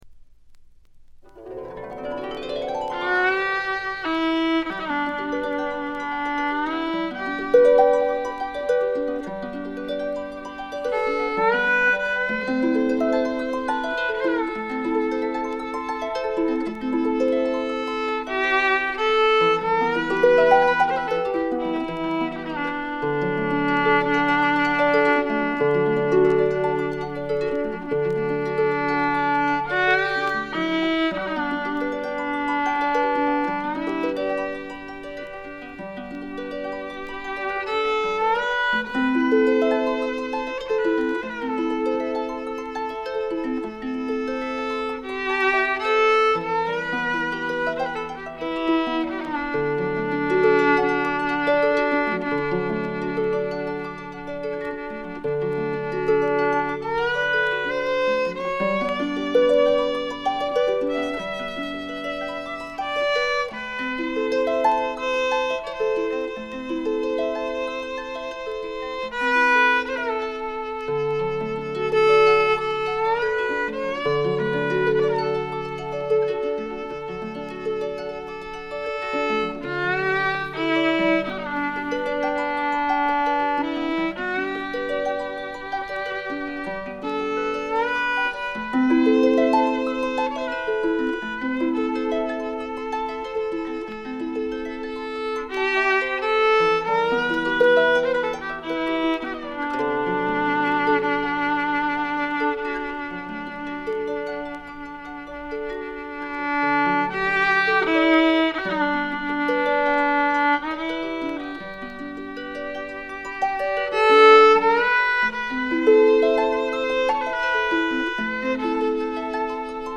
ところどころでバックグラウンドノイズ、チリプチ。散発的なプツ音少々。
試聴曲は現品からの取り込み音源です。
Fiddle
Harp [Clarsach]